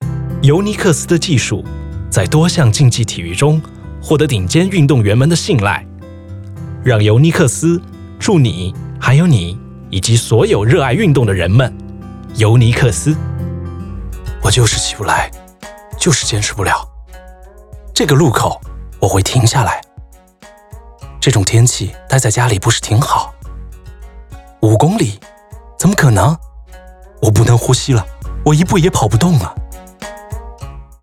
Capable of giving you exactly what you need for your next VO project.
Business Corporate Documentaries English Formal Mandarin Trailer CHN004_ENGLISH_FORMAL_2 CHN004_CHINESE_CASUAL CHN004_ENGLISH_FORMAL_1